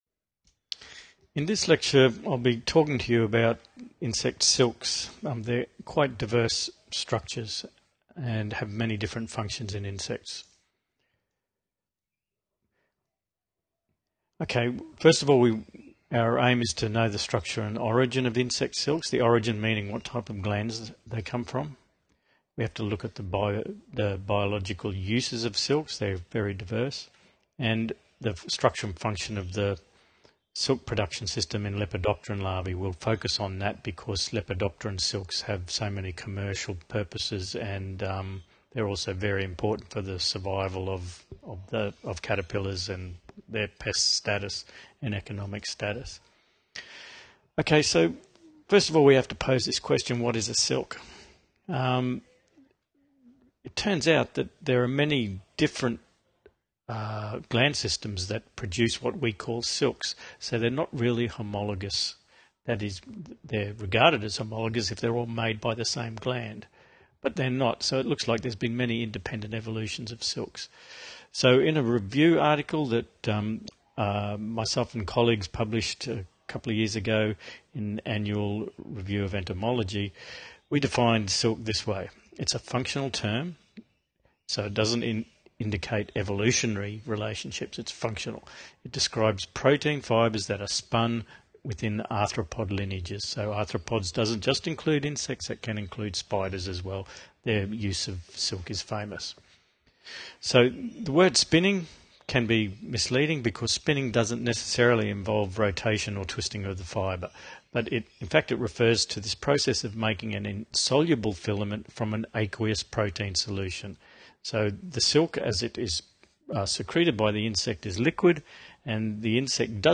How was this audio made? NEW audio file (redone to remove skips); you may want to listen to this file while reading the pdf